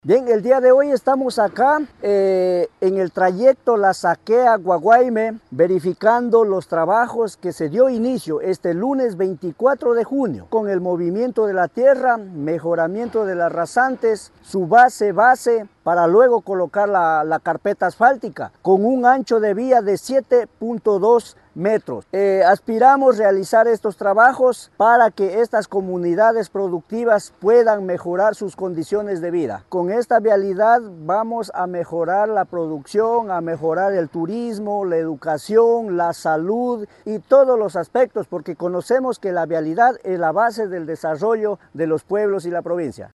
VÍCTOR SARANGO, VICEPREFECTO
VICTOR-SARANGO-VICEPREFECTO.mp3